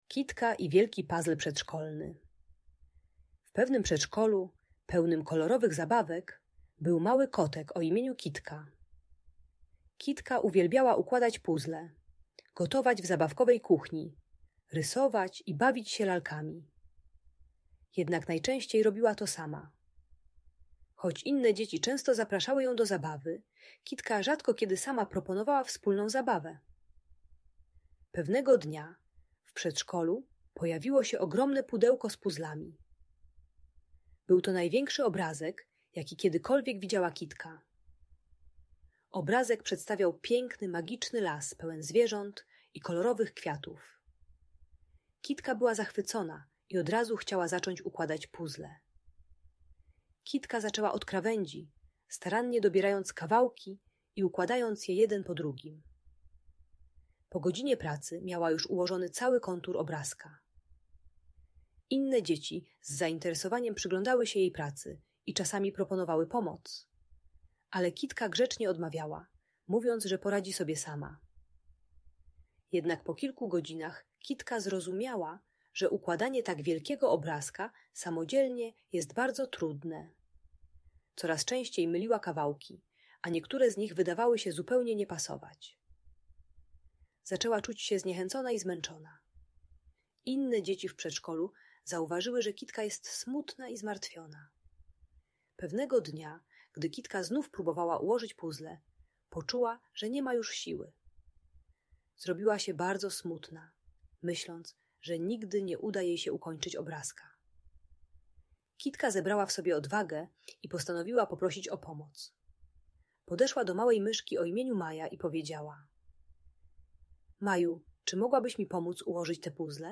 Bajka dla dziecka które nie umie prosić o pomoc i bawi się samo w przedszkolu.